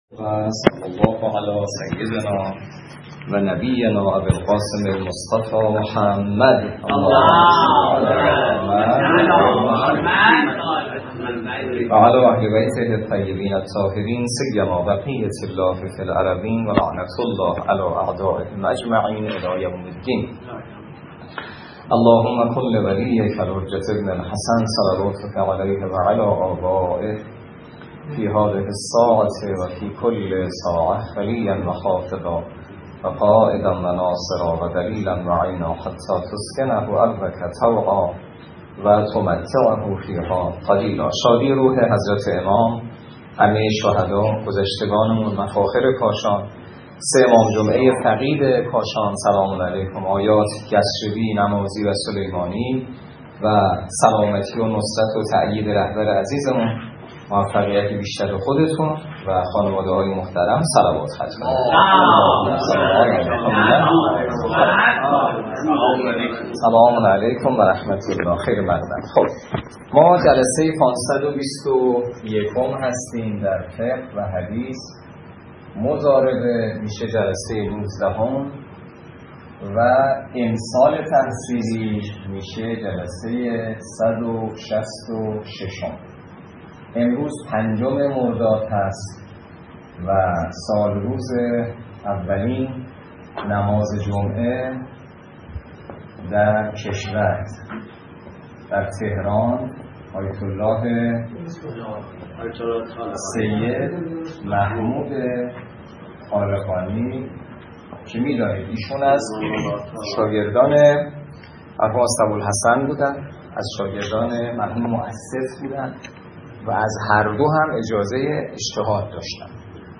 روایات ابتدای درس فقه موضوع: فقه اجاره - جلسه ۱۹